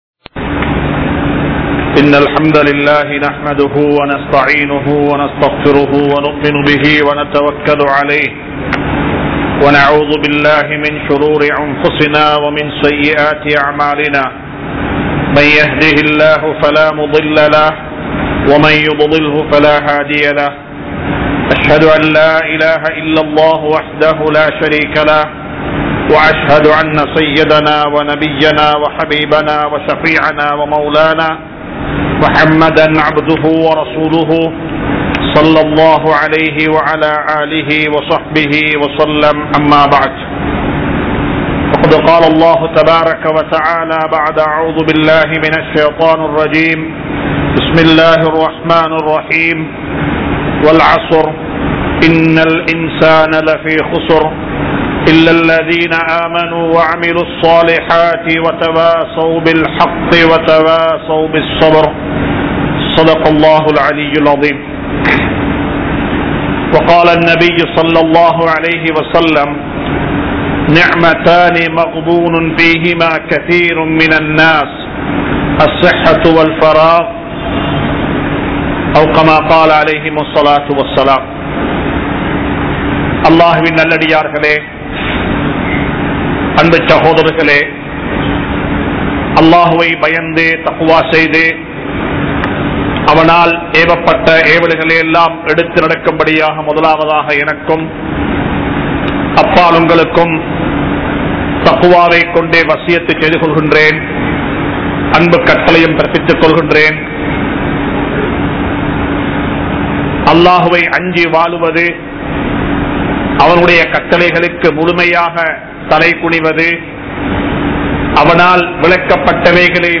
Kalvi Katpathan Noakkam Enna? (கல்வி கற்பதன் நோக்கம் என்ன?) | Audio Bayans | All Ceylon Muslim Youth Community | Addalaichenai
Kollupitty Jumua Masjith